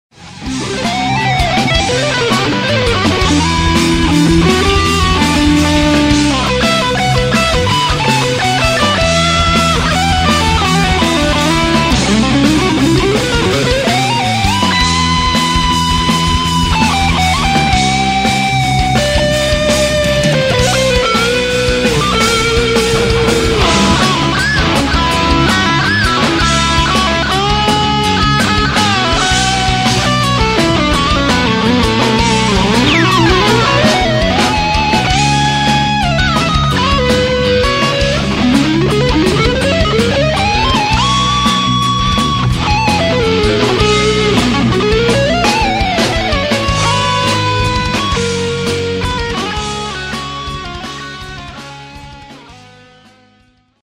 solos